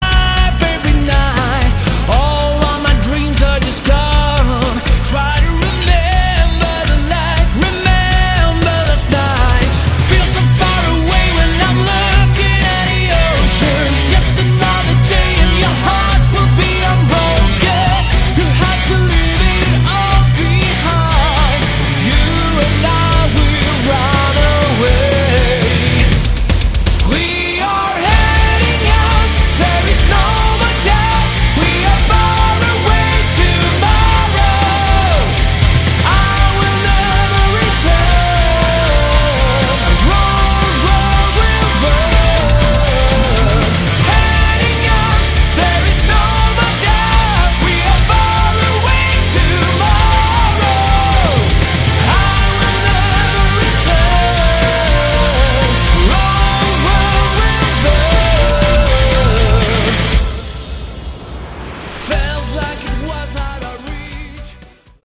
Category: Melodic Metal
guitars, lead vocals on tracks 3, 8, 11
drums